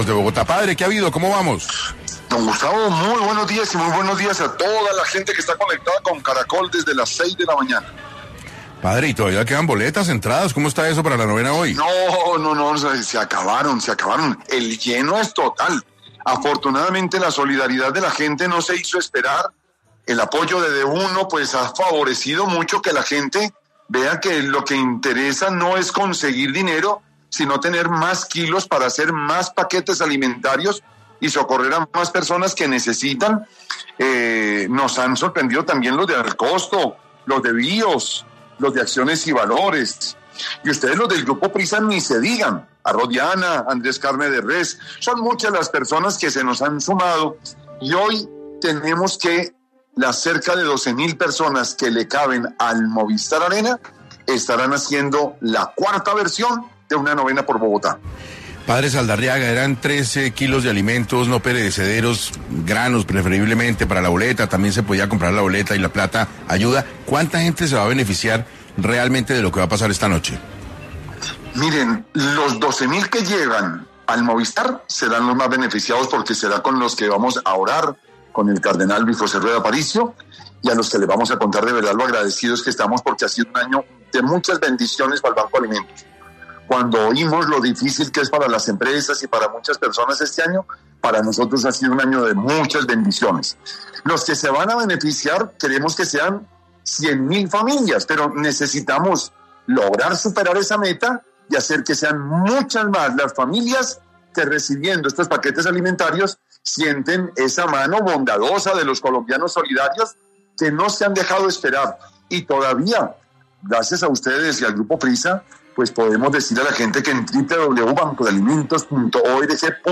aseguró en entrevista con 6AM que está muy feliz y agradecido por toda la generosidad y colaboración de las familias bogotanas que se sumaron a esta bonita obra